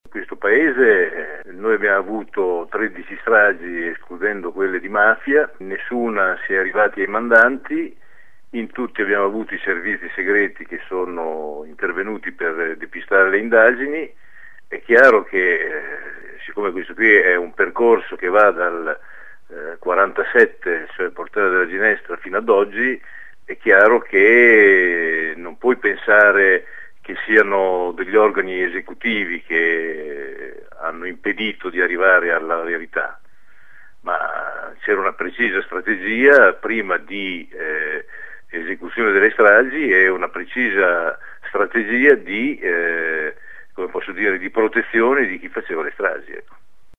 Ascolta Paolo Bolognesi, presidente dell’associazione familiari delle vittime